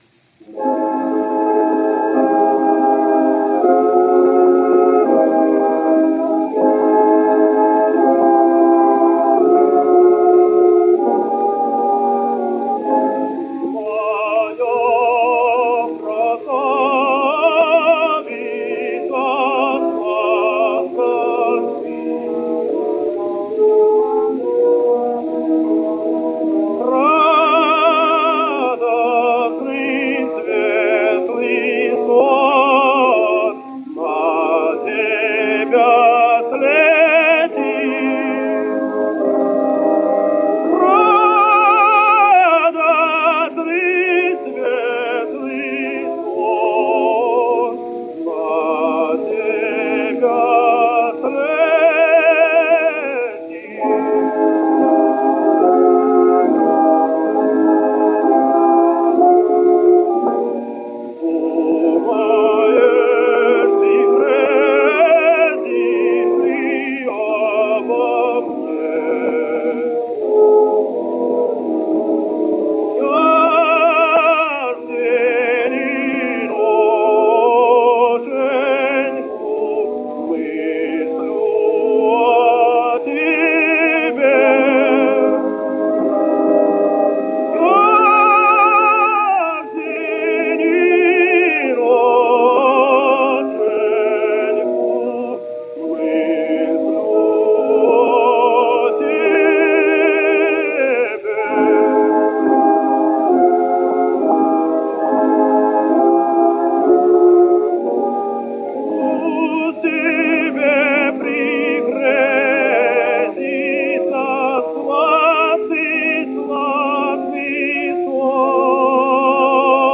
Belarusian Tenor